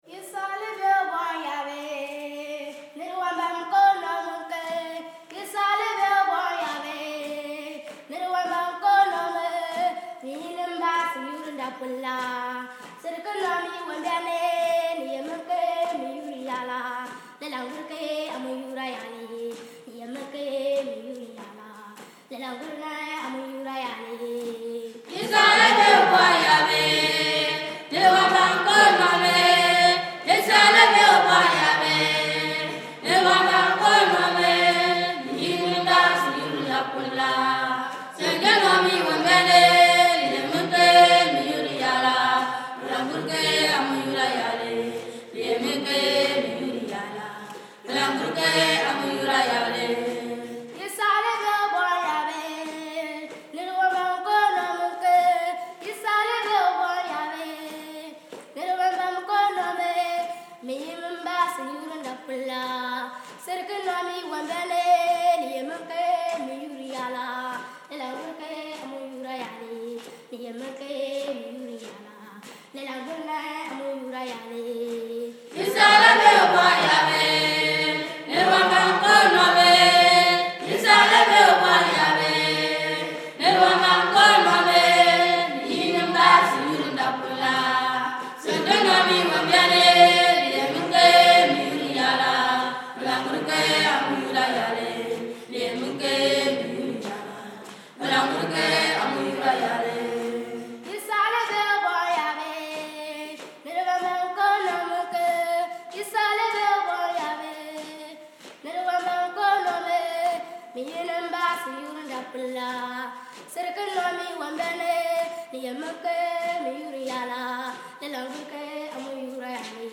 We have several things left on our ToDo-list: Record the Bible Stories; Record some more choirs (we already have probably 2 hrs of fantastic traditional “caller / response” music… we can’t wait to upload an example for you); Take some video for a possible Prayercast video; and check in on the water filtration units we left last year.
The first part is the “caller”, so don’t turn up your speakers too loud or you will get quite a surprise when the choir joins in.
The shuffling you hear in the background is their stomp-step.
The “caller” style is the most common choir type here, and the melodies and rhythms come from their homes and villages.
The cement walls and tin roof of the church gave wonderful reverb.